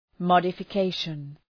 Shkrimi fonetik{,mɒdəfə’keıʃən}
modification.mp3